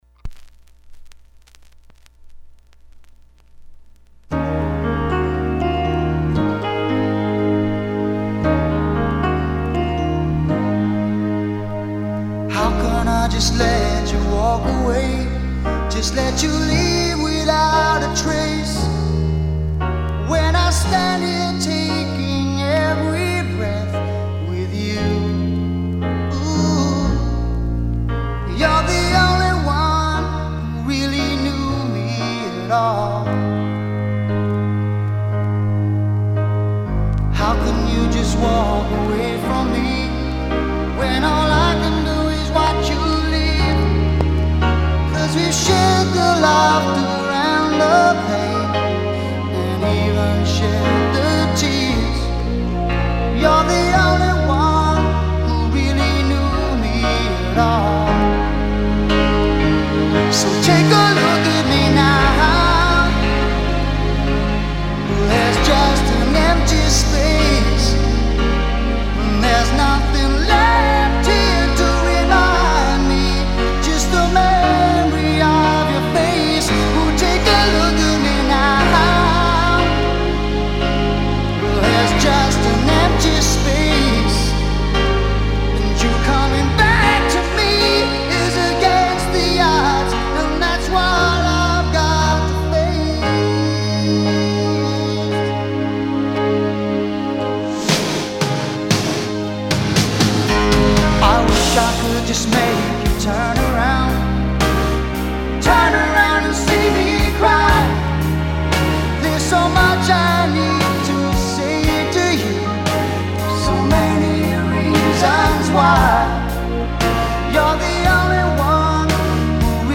crackly 45